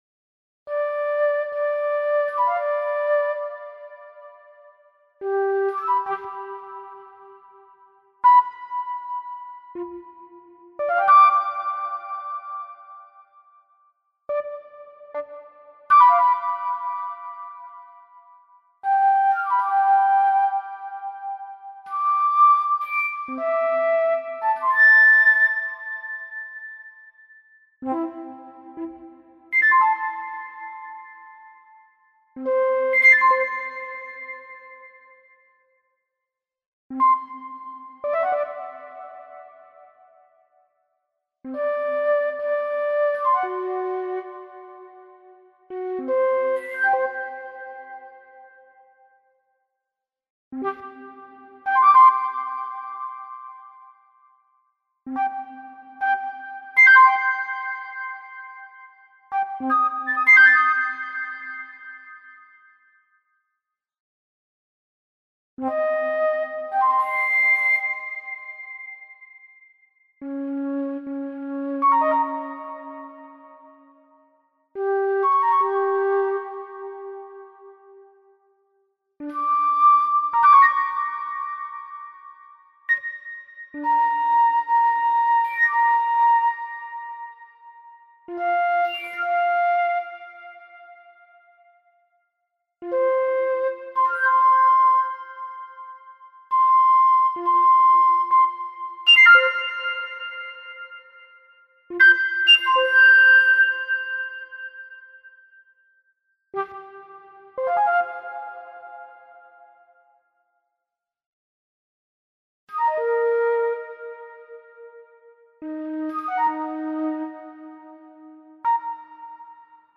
You hear a flute concert.